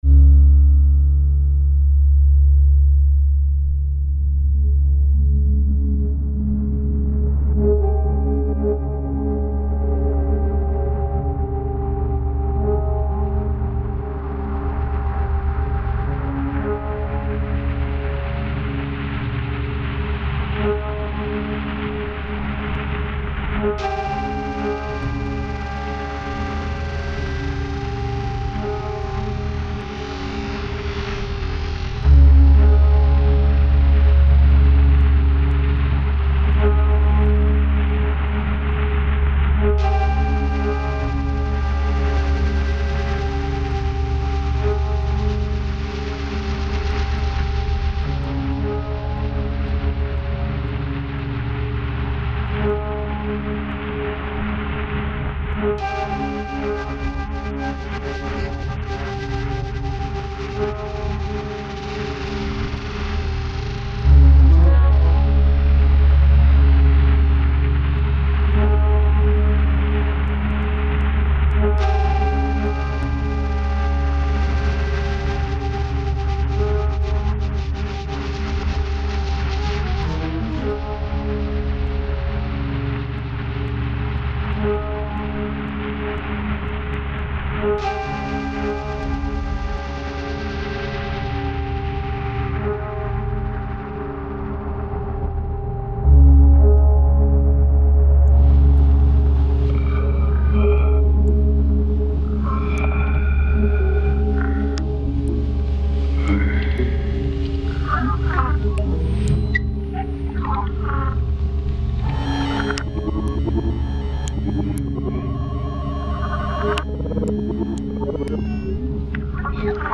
Sur scène en solo pendant les projections du « prix découverte ». 3 min d’images pour chacun des 10 photographes, 30 min de musiques originales.
Avec : échantillons vieux films, cymbale basse, traitement électronique